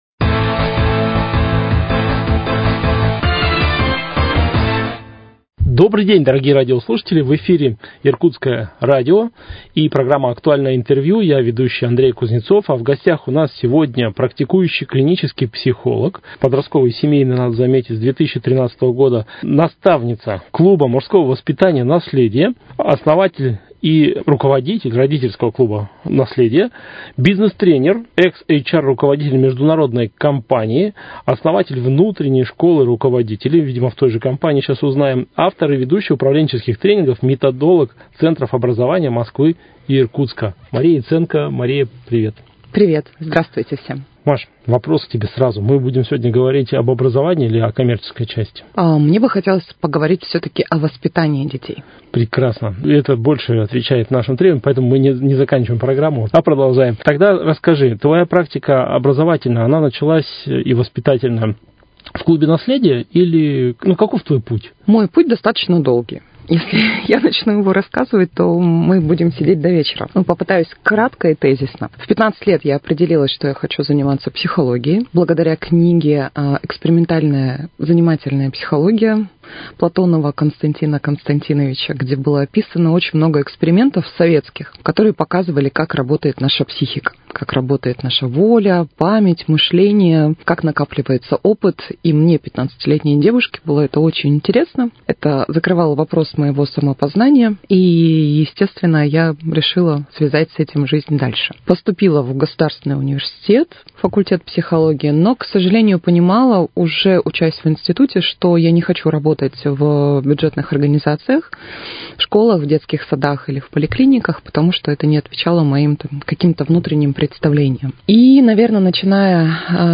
Актуальное интервью